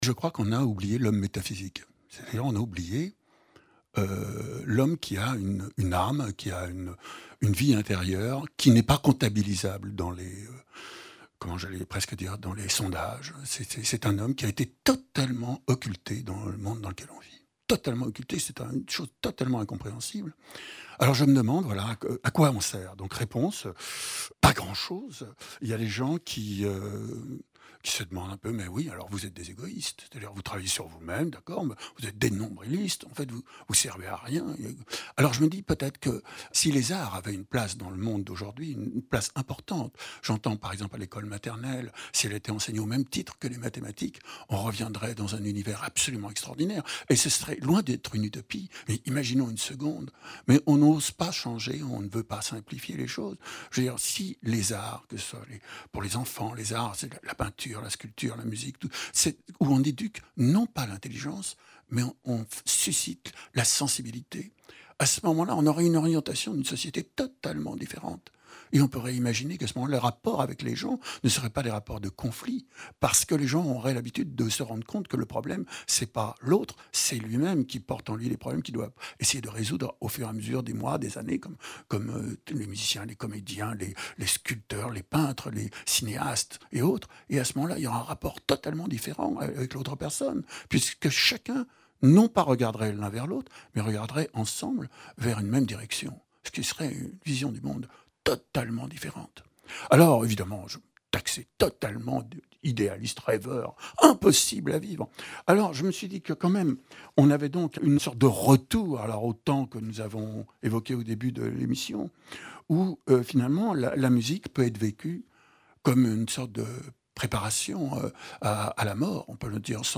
Extraits de l’émission Black & Blue d’Alain Gerber sur France Culture